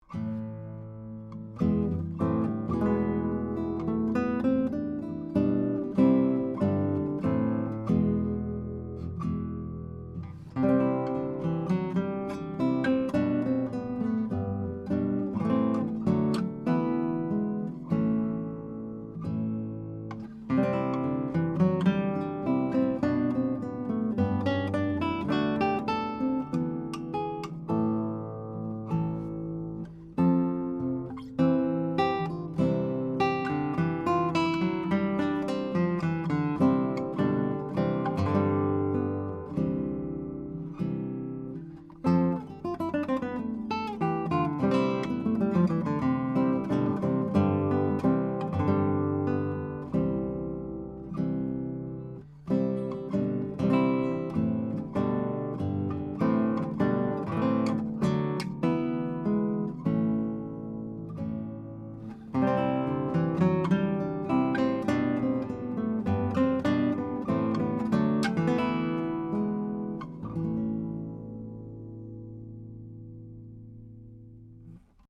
Here are 14 MP3s of the Chandler TG Channel using a Neumann TLM67 on Milagro 10-String Classical Harp Guitar into a Metric Halo ULN-8 converter, to Logic, with no additional EQ or any other effects: